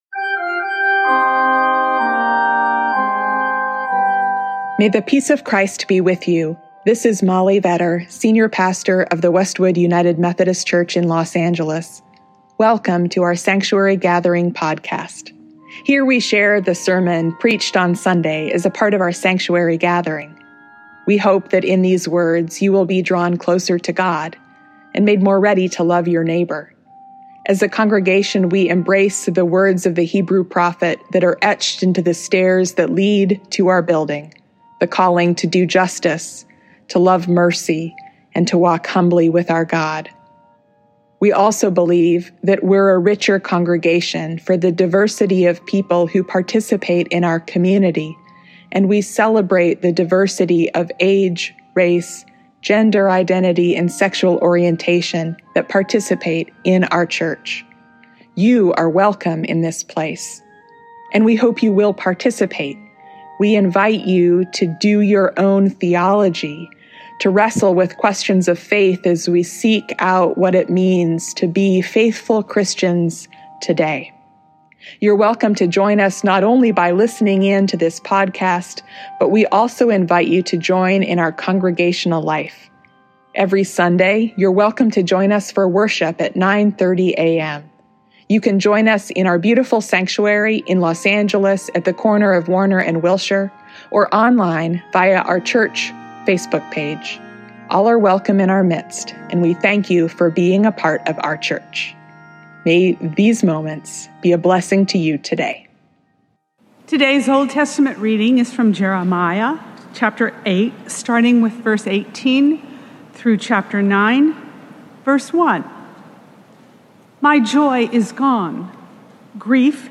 This Sunday, we join with Christians around the US in an observance of a Service of Lament, Repentance, & Action against gun violence.